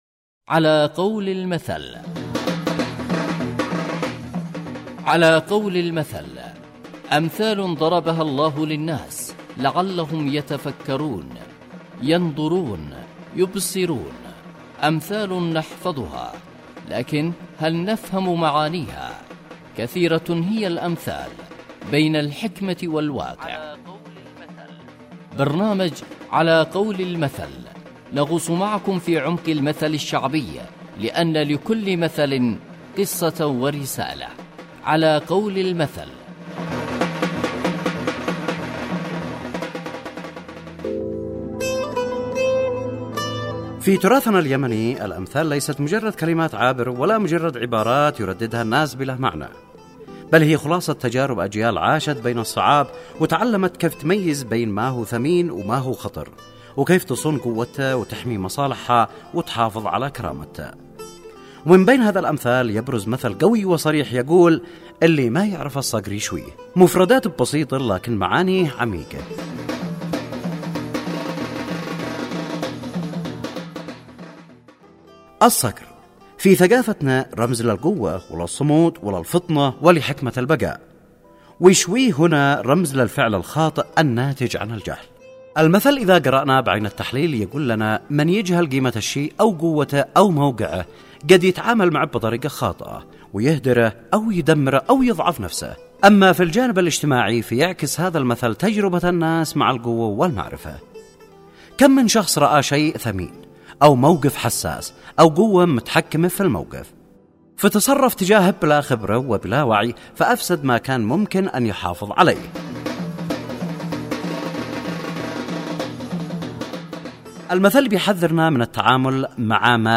برنامج إذاعي يحكي عن معاني الامثال والحكمة منها كالمثل العسكري أو المثل المرتبط بأية قرآنية او المثل الشعبي . ويقدم تفسير للمثل والظروف التي أحاطت بالمثل وواقع المثل في حياتنا اليوم ويستهدف المجتمع.